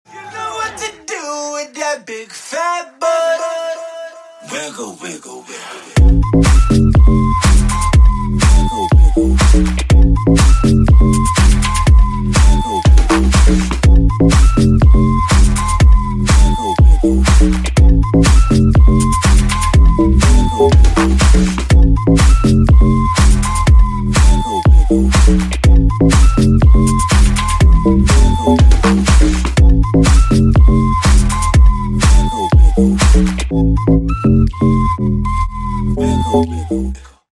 Рингтоны Ремиксы
Танцевальные Рингтоны